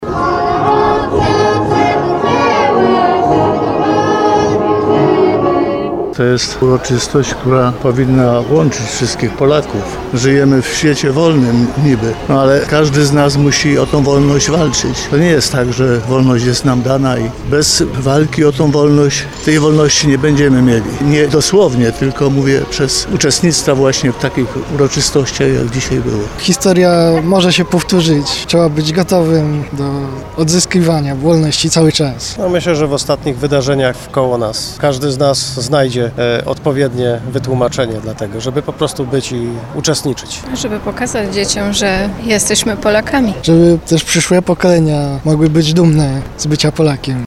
– Jesteśmy tu, by pokazać naszym dzieciom, że jesteśmy Polakami – mówili uczestnicy.